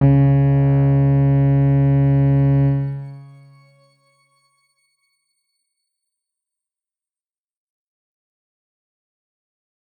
X_Grain-C#2-pp.wav